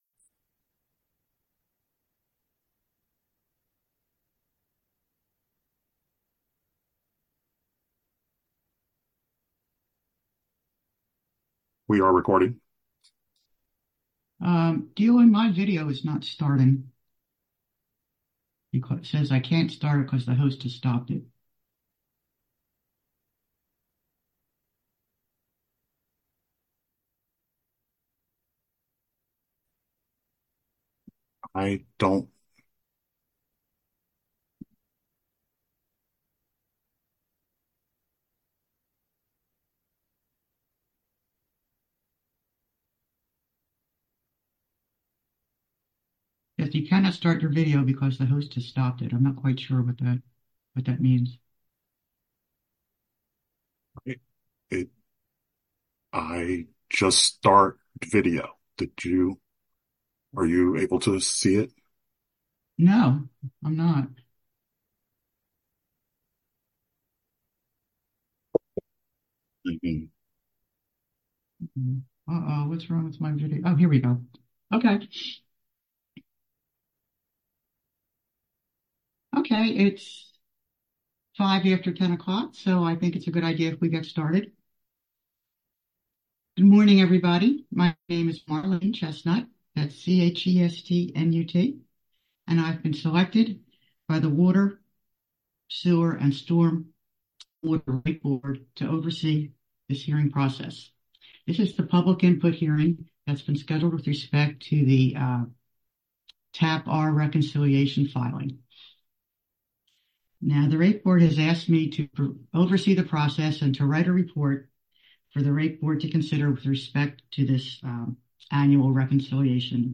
2024-TAP-R-Public-Hearing.mp3